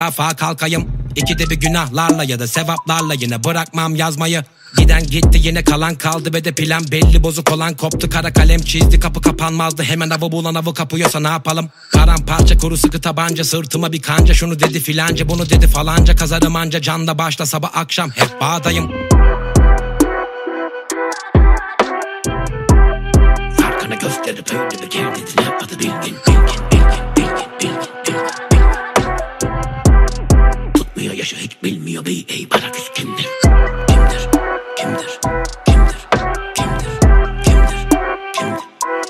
Kategorien Rap/Hip Hop